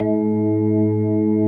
B3 ROCKG#2.wav